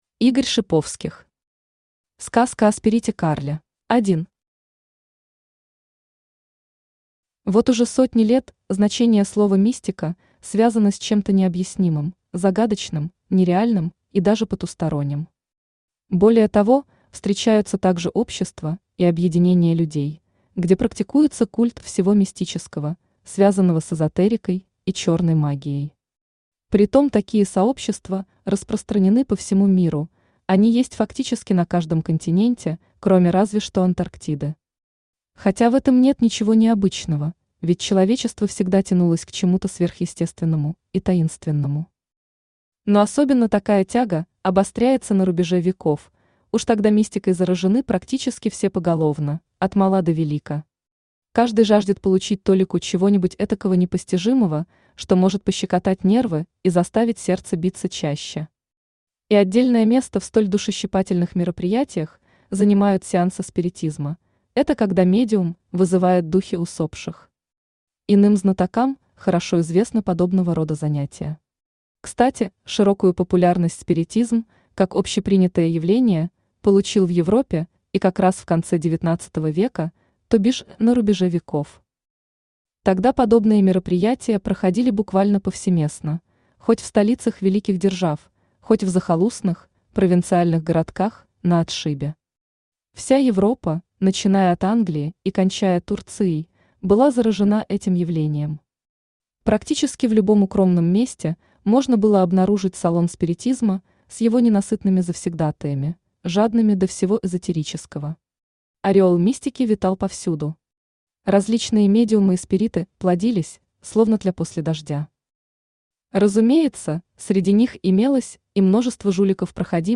Аудиокнига Сказка о спирите Карле | Библиотека аудиокниг
Aудиокнига Сказка о спирите Карле Автор Игорь Дасиевич Шиповских Читает аудиокнигу Авточтец ЛитРес.